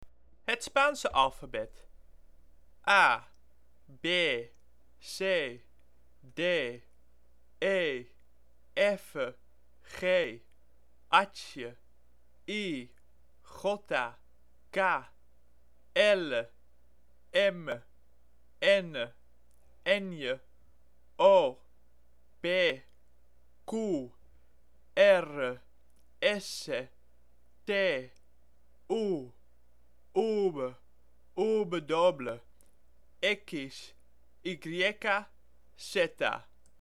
Spaanse Les